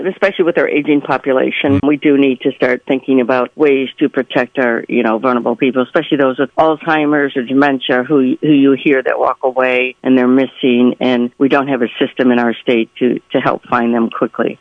The full conversation with State Representative Schmaltz, along with other recent guest interviews, is available to listen to and download at the link below.